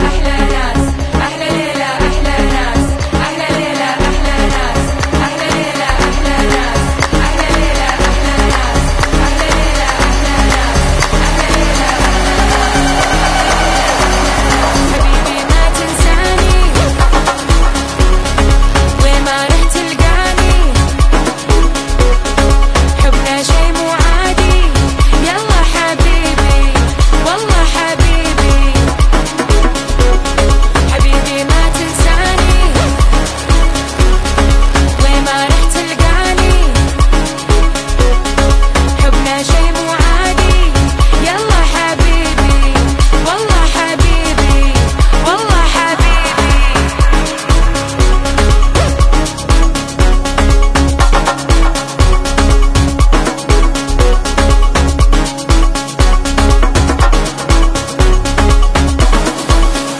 Ahlelele ahlelas meme Sound Effect
Ahlelele-Ahlelas-Meme-Sound-Effect.mp3